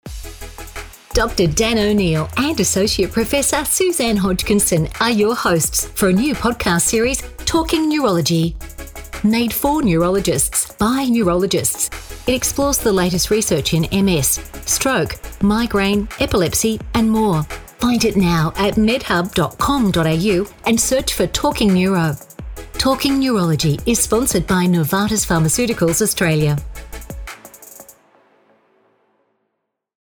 Female
English (Australian)
Adult (30-50), Older Sound (50+)
Podcasting
All our voice actors have professional broadcast quality recording studios.